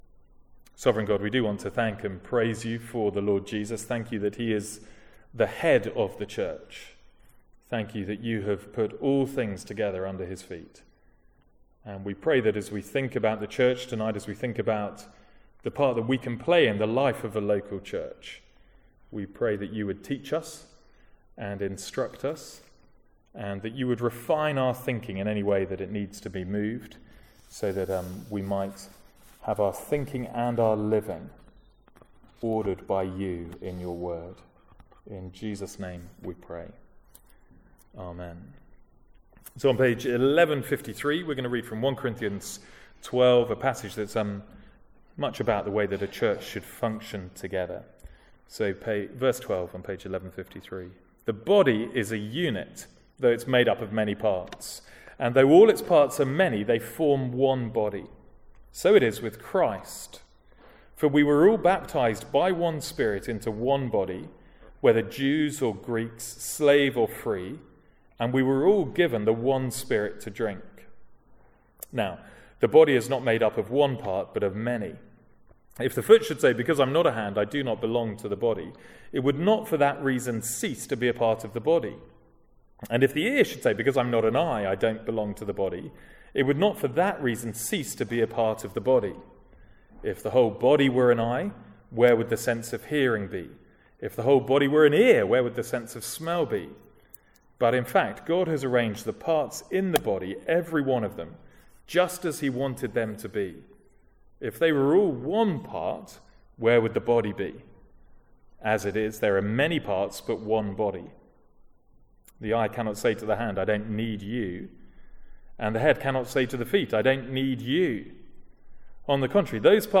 Sermons | St Andrews Free Church
From the evening service on Church membership on 9/3/14.